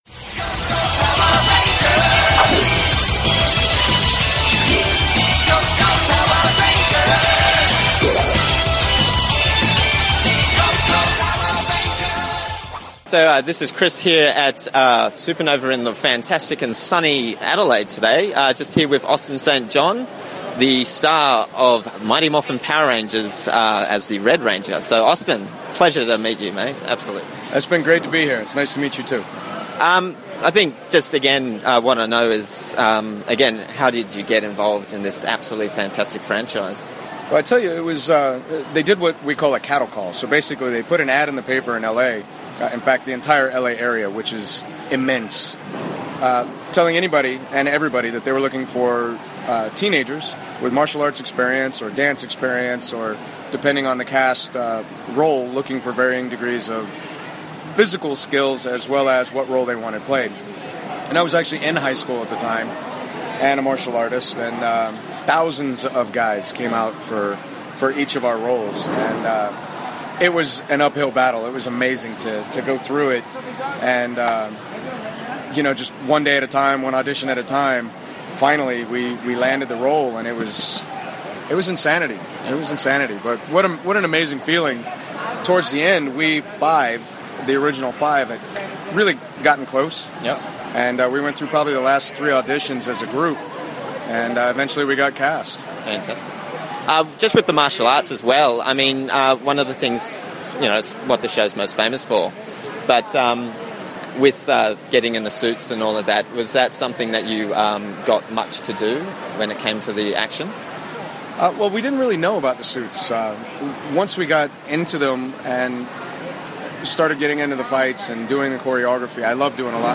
Supanova Adelaide 2014: Interview with Austin St John (Original Red Power Ranger)
Austin St John Interview
austin-st-john-interview-supanova-adelaide-2014.mp3